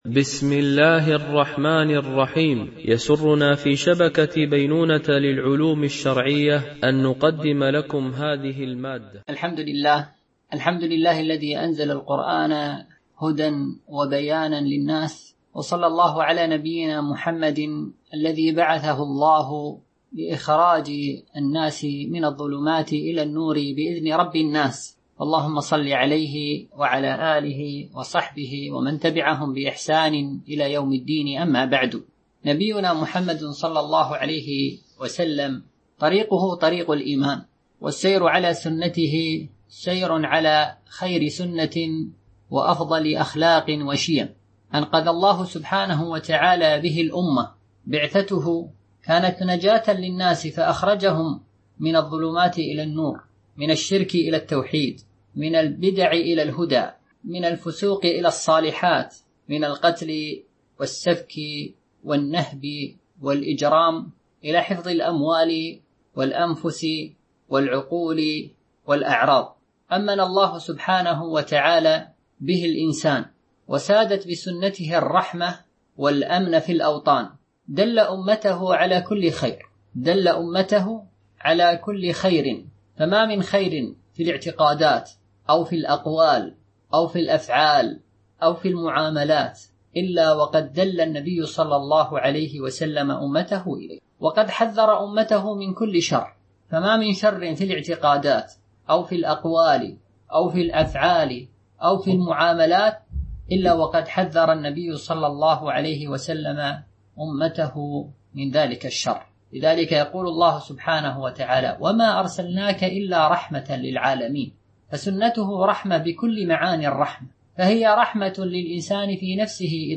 سلسلة محاضرات نسائم إيمانية وقيم أخلاقية